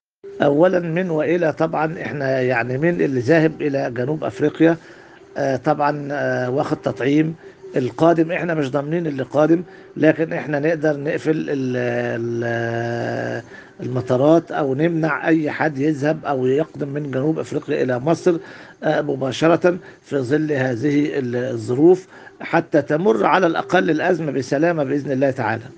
الحوار الصحفي التالي